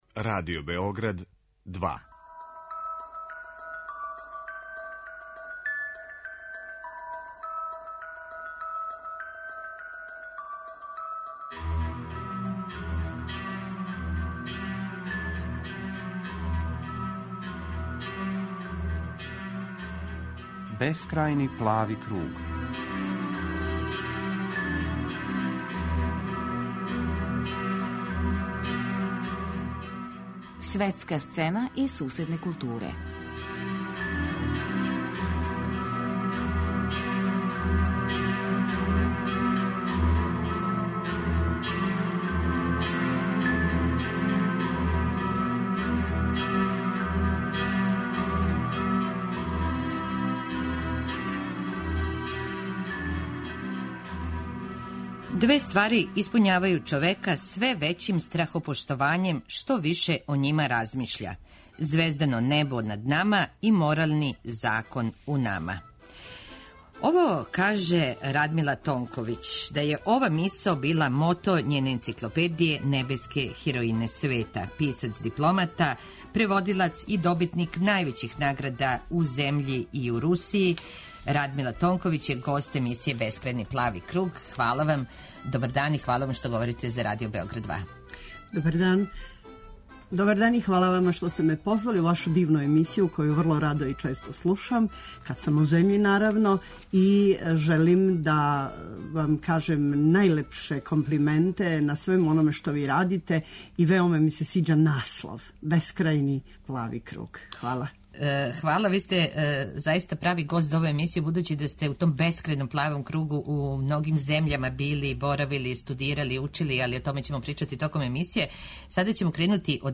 Гошћа